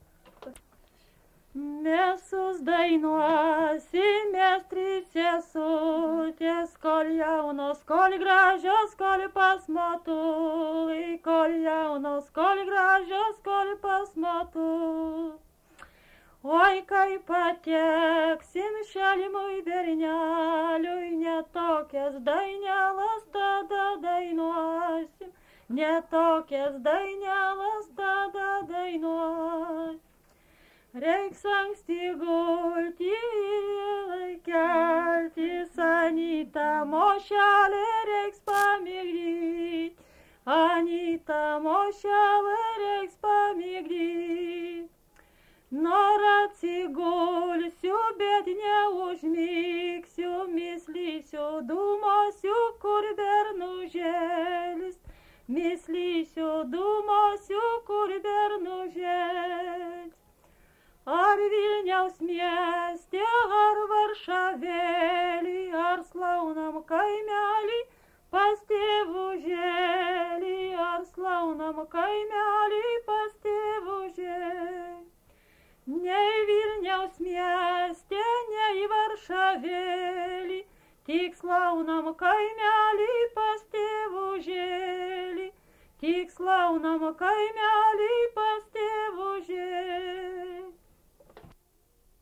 Dalykas, tema daina
Erdvinė aprėptis Nemunaitis
Atlikimo pubūdis vokalinis